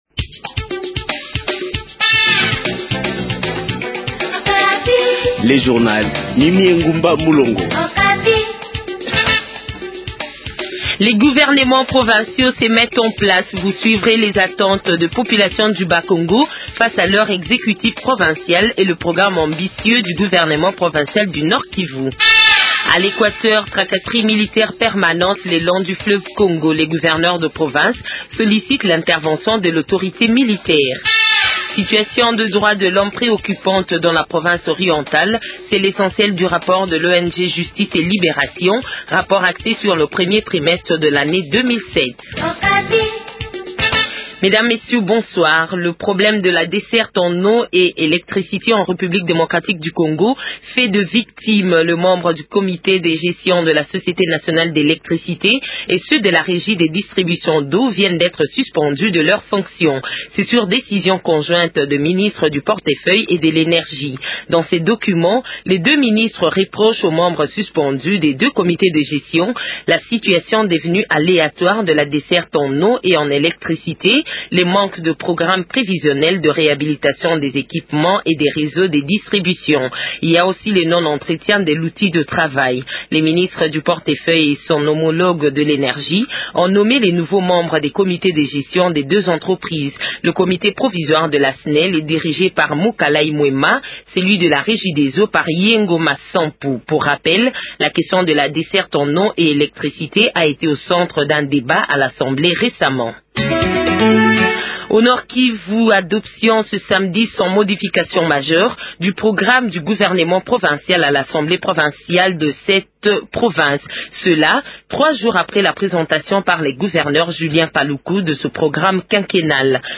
280407-a-f-Journal Francais Soir